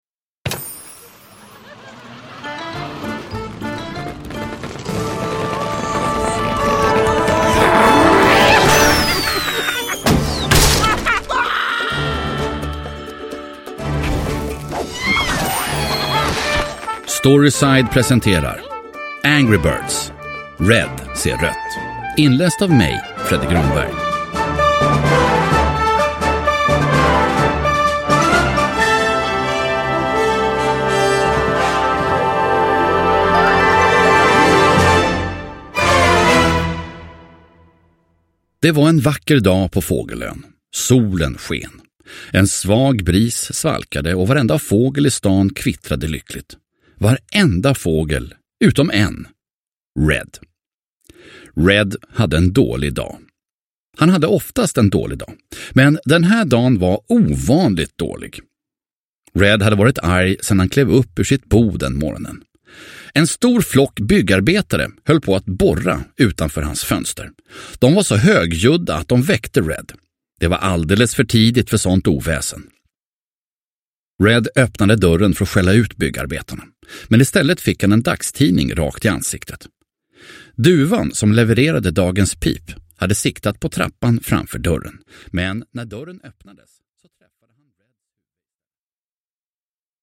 Angry Birds - Red ser rött – Ljudbok – Laddas ner
Uppläsare: Fredde Granberg